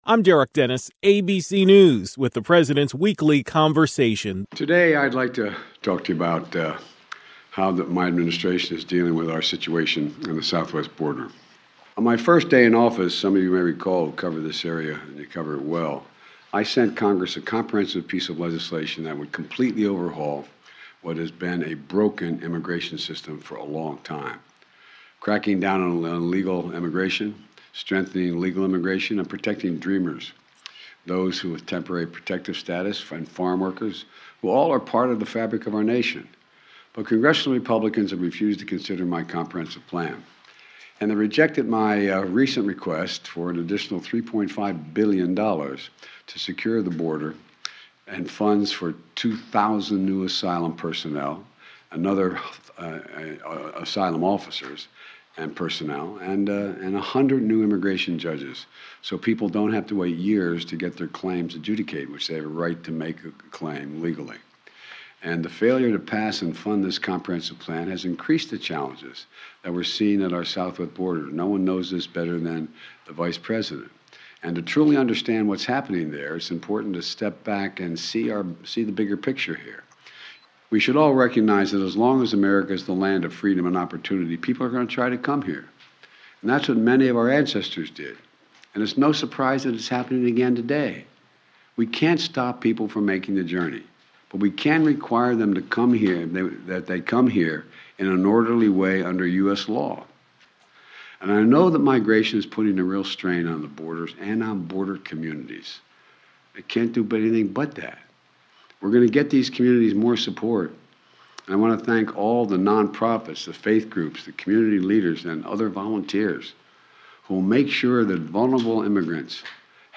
President Biden delivered a speech on border security and enforcement.